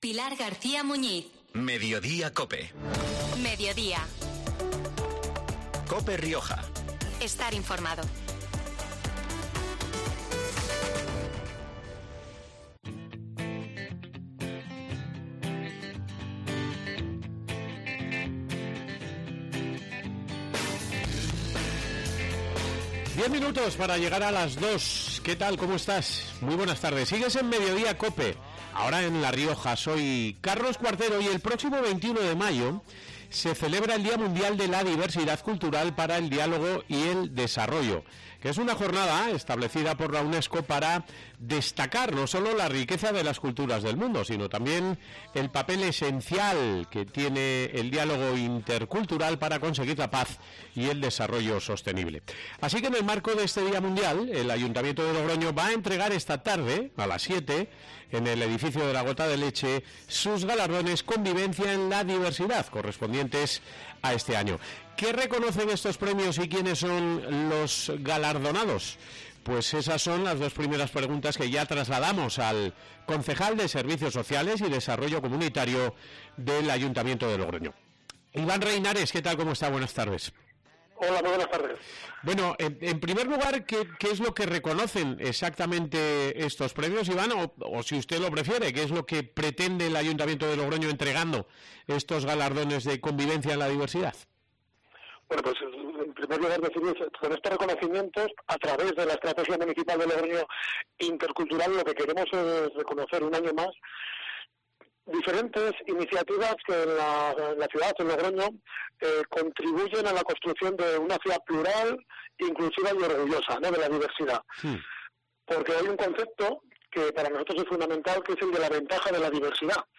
Ivan Reinares, concejal de Servicios Sociales y desarrollo Comunitario, en los estudios de COPE Rioja.